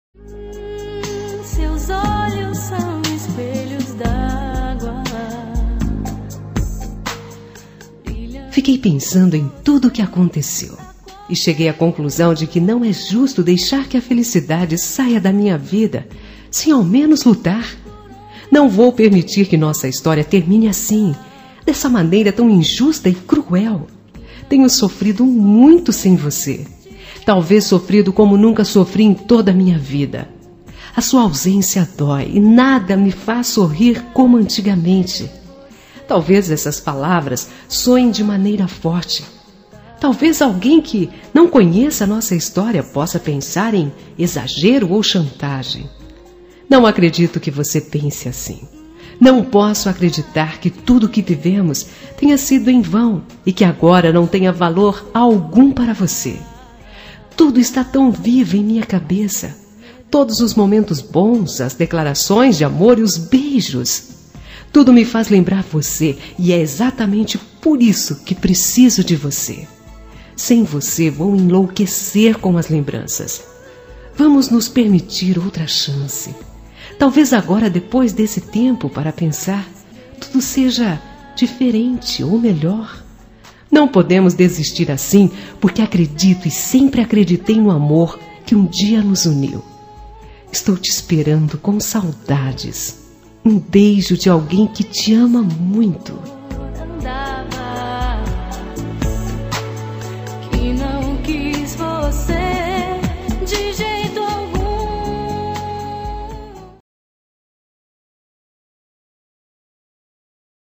Telemensagem de Reconciliação – Voz Feminina – Cód: 7550